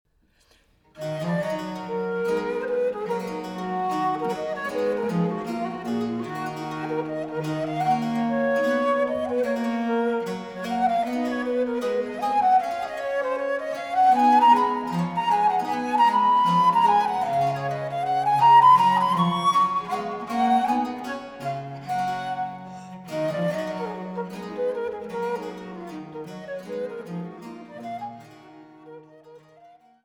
Traversflöte
Allemande